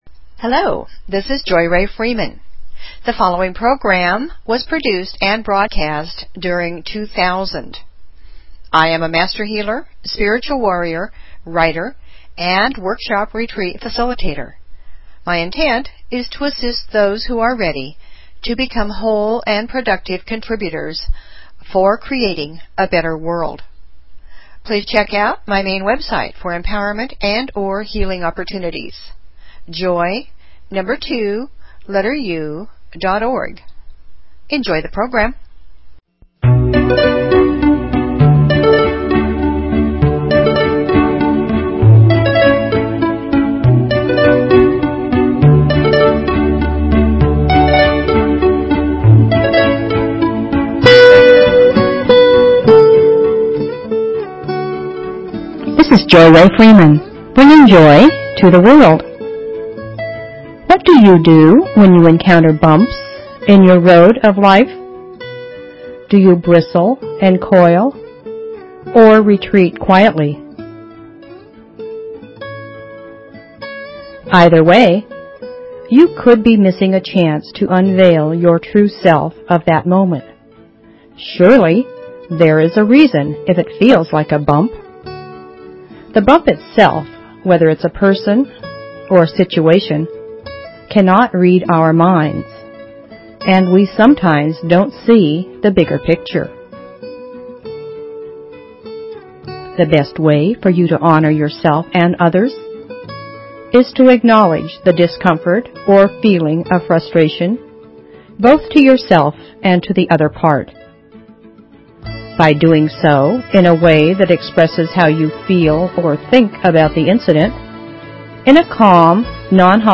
Talk Show Episode, Audio Podcast, Joy_To_The_World and Courtesy of BBS Radio on , show guests , about , categorized as
Music, poetry, affirmations, stories, inspiration . . .